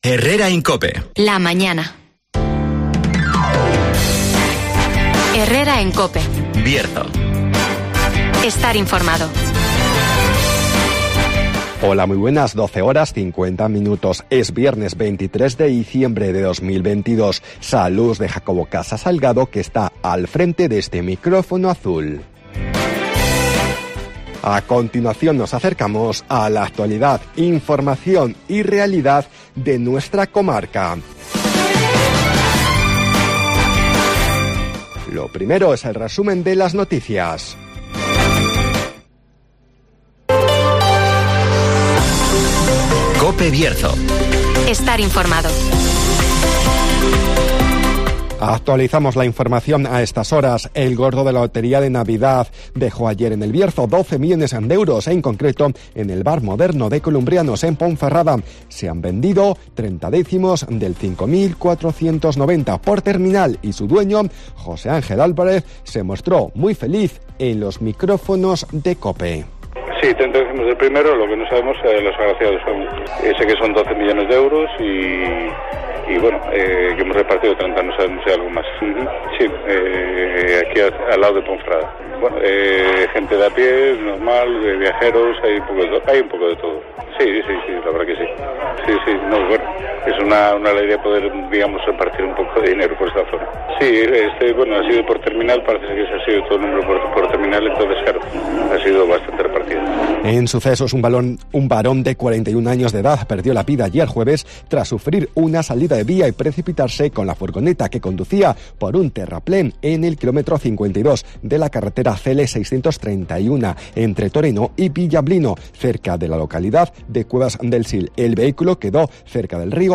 AUDIO: Resumen de las noticias, el tiempo y la agenda